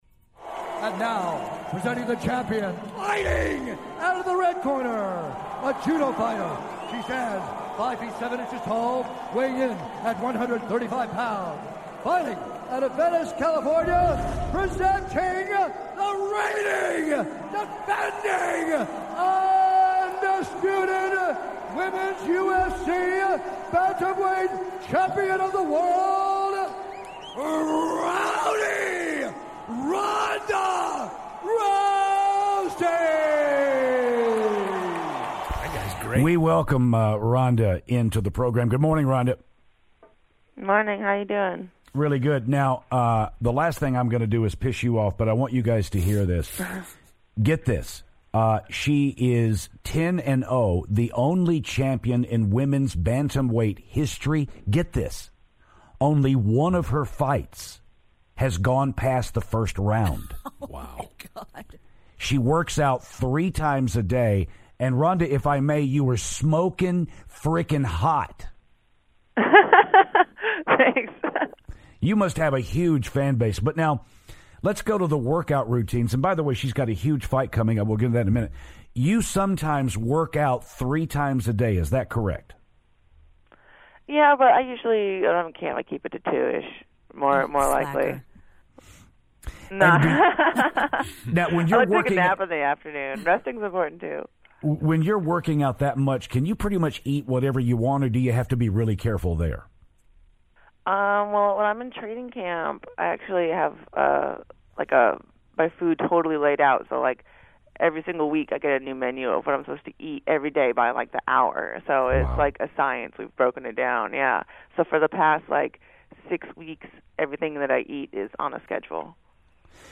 UFC Champ Ronda Rousey calls the show